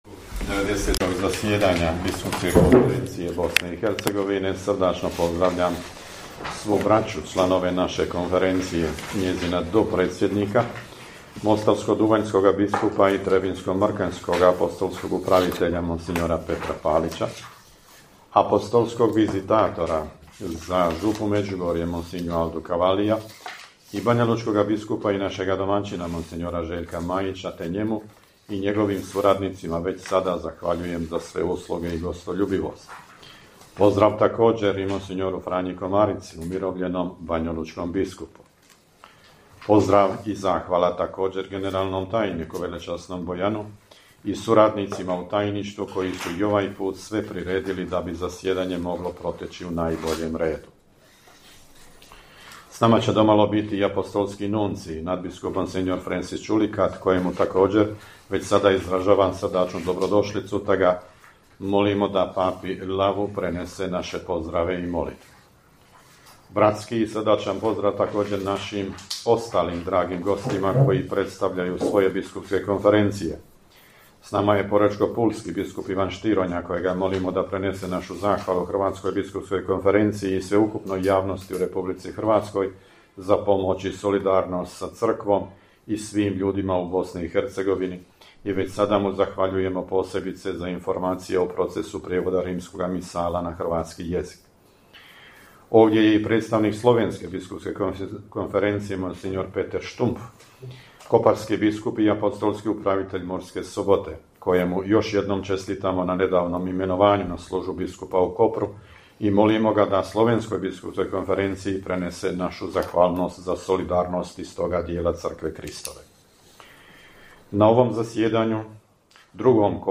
Banja Luka: Pozdravni govor predsjednika BK BiH mons. Tome Vukšića na početku 93. redovitog zasjedanja BK BiH
Na početku 93. redovitoga zasjedanja Biskupske konferencije BiH, koje je počelo u srijedu 16. srpnja u Banjoj Luci, vrhbosanski nadbiskup i apostolski upravitelj Vojnog ordinarijata u BiH, predsjednik BK BiH mons. Tomo Vukšić uputio je pozdravni govor koji prenosimo u cijelosti.